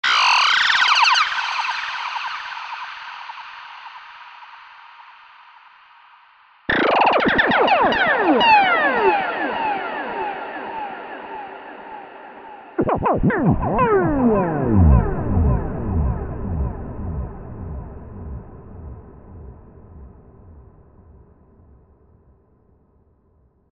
Because of this, even if it is digital synthesis, it sounds "warm".
All these demos are recorded directly from the ZynAddSubFX without audio processing with another program (well, exeption cutting/ OGG Vorbis compressing).